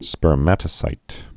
(spər-mătə-sīt, spûrmə-tə-)